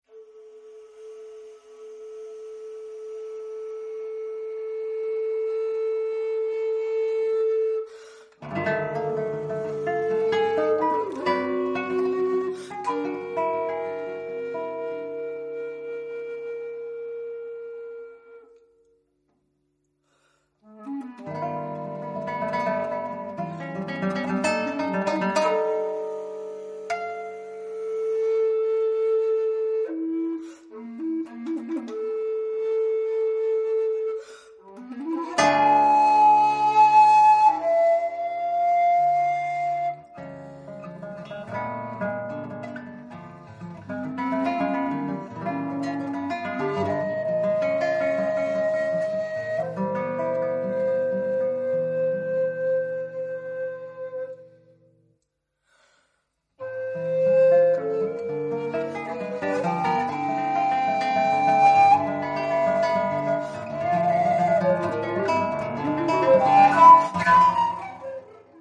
Australian classical music
one of Australia's leading recorder players
Classical